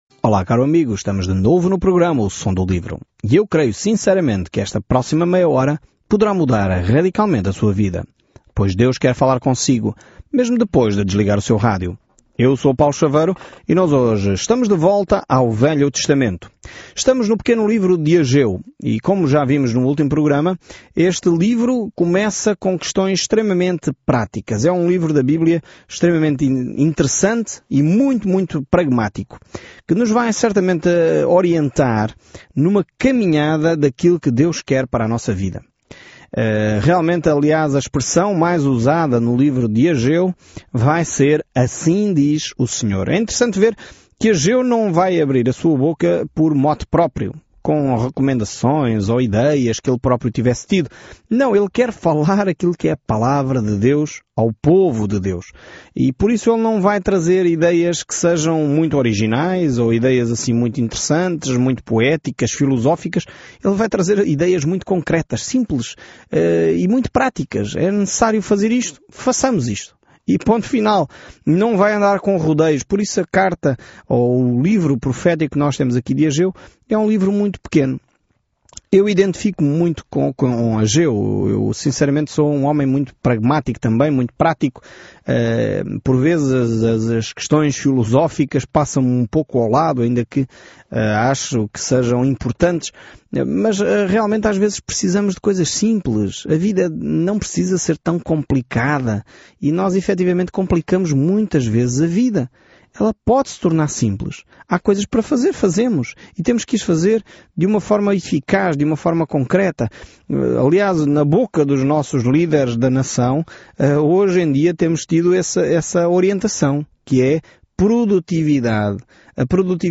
Escritura AGEU 1:1-4 Dia 1 Iniciar este Plano Dia 3 Sobre este plano A atitude de Ageu de “fazer isso” incita um Israel distraído a reconstruir o templo depois de retornar do cativeiro. Viaje diariamente por Ageu enquanto ouve o estudo em áudio e lê versículos selecionados da palavra de Deus.